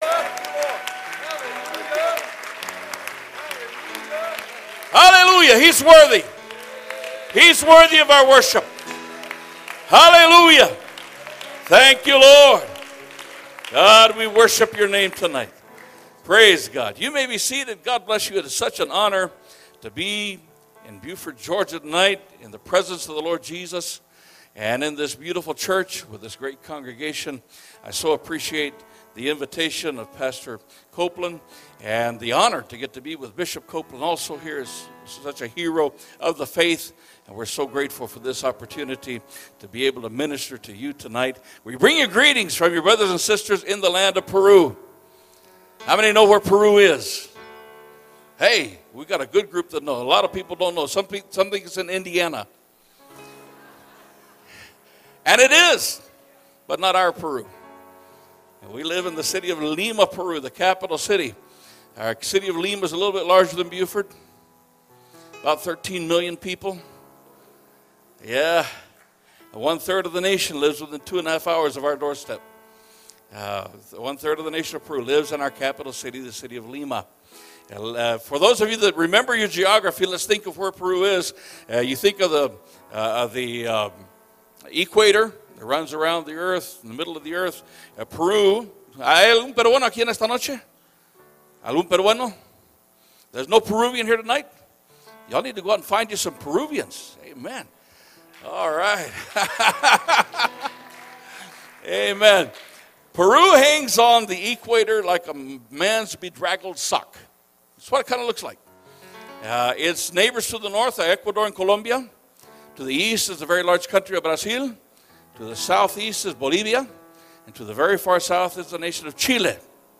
First Pentecostal Church Preaching 2021